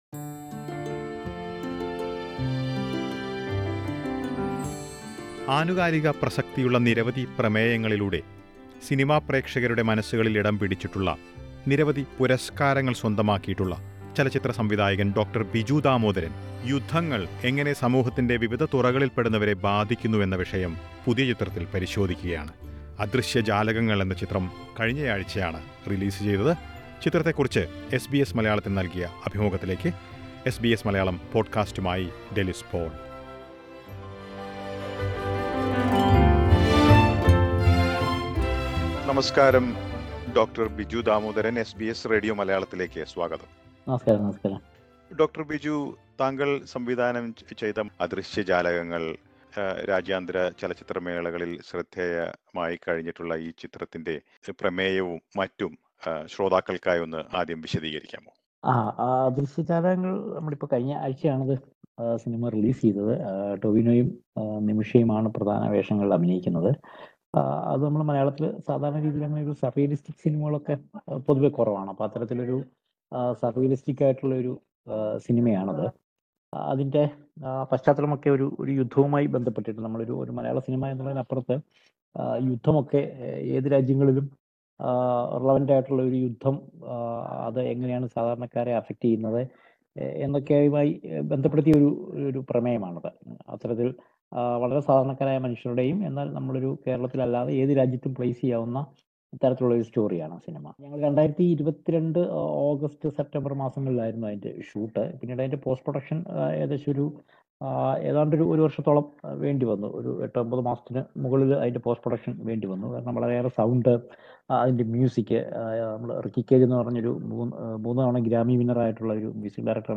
ആനുകാലിക പ്രസക്തിയുള്ള നിരവധി പ്രമേയങ്ങളിലൂടെ പ്രക്ഷകമനസുകളിൽ ഇടം പിടിച്ചിട്ടുള്ള സംവിധായകൻ ഡോ ബിജു ദാമോദരൻ യുദ്ധങ്ങൾ എങ്ങനെ സമൂഹത്തിന്റെ വിവിധ തുറകളിൽപ്പെടുന്നവരെ ബാധിക്കുന്നു എന്ന വിഷയമാണ് പുതിയ ചിത്രത്തിൽ പരിശോധിക്കുന്നത്. അദൃശ്യ ജാലകങ്ങൾ എന്ന ചിത്രത്തെക്കുറിച്ച് ഡോ ബിജു എസ് ബി എസ് മലയാളത്തോട് വിവരിക്കുന്നത് കേൾക്കാം മുകളിലെ പ്ലെയറിൽ നിന്ന്.